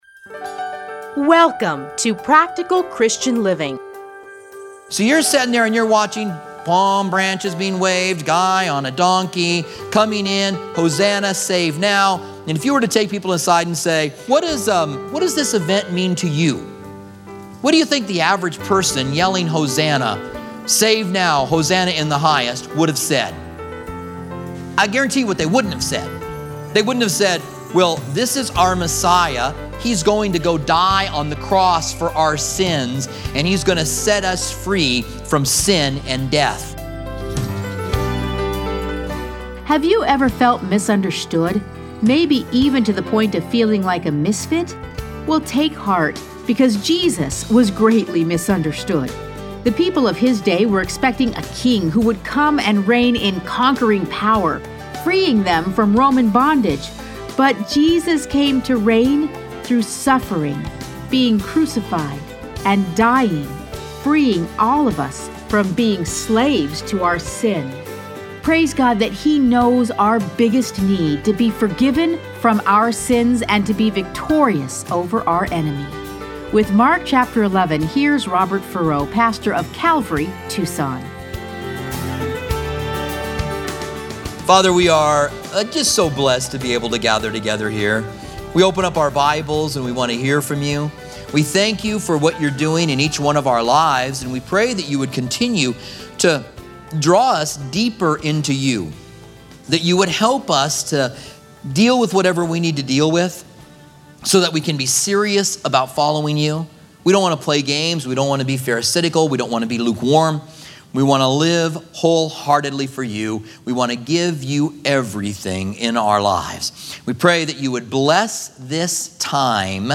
Listen to a teaching from Mark 11.